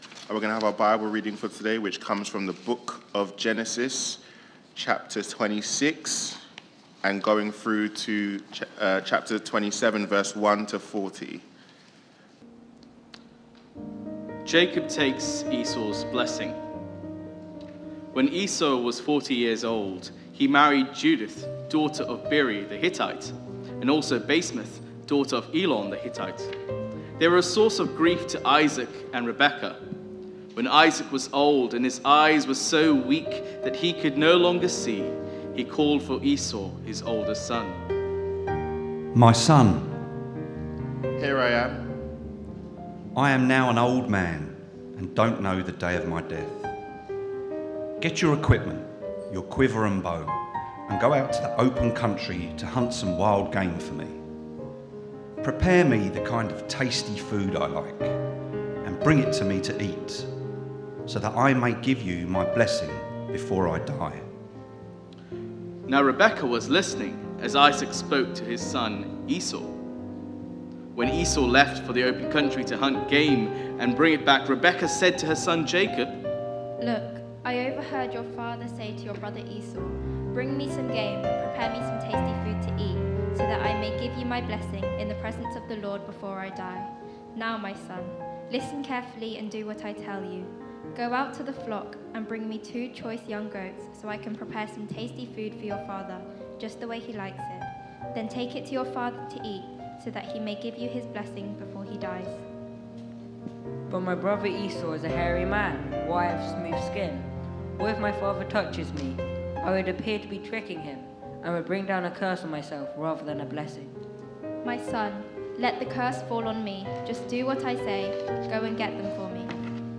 Media for Sunday Service
Theme: Blessings, Betrayal, and God's Bigger Plan Sermon